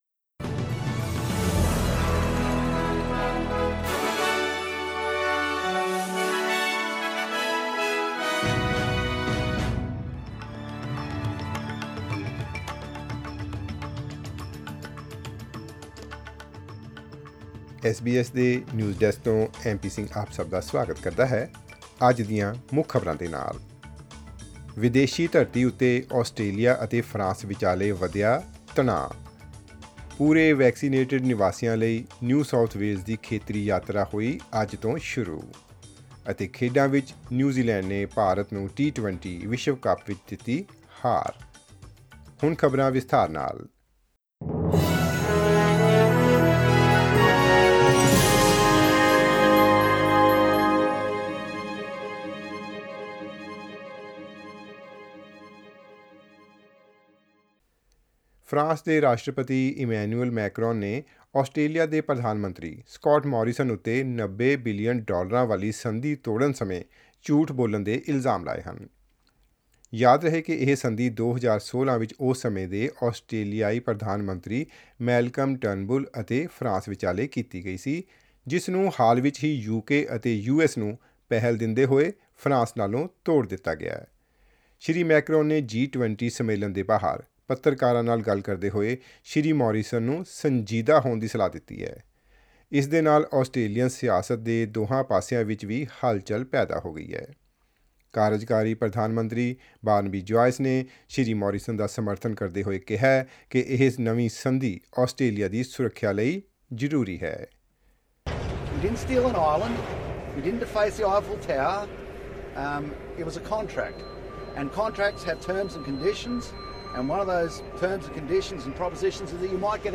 Listen to the latest news headlines in Australia from SBS Punjabi radio.